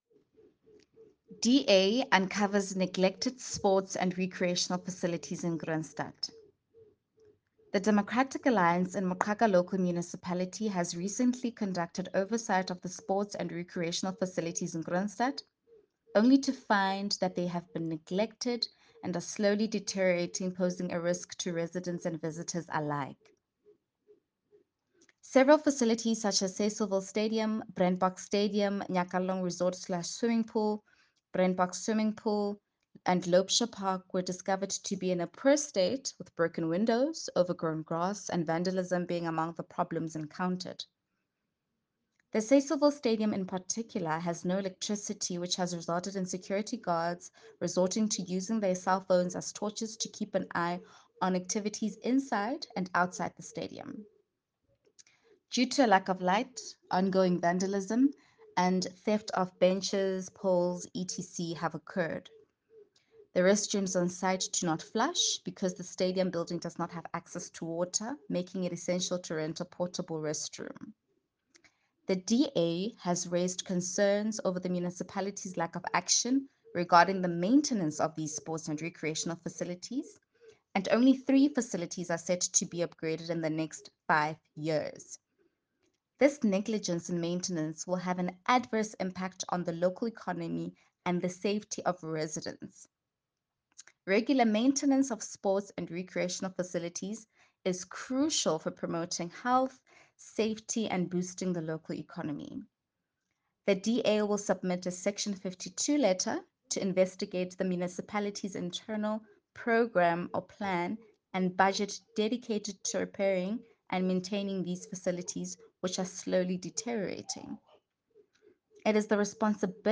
Sesotho soundbites by Cllr Mbali Mnaba.